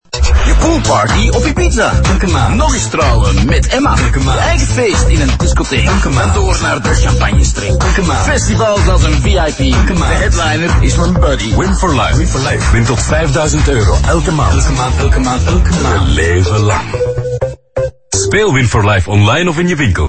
The new visual and auditory identity of Win for Life became more playful, and the campaign now successfully engages a younger demographic.
A cheerful pink parrot that keeps repeating: You could win every month!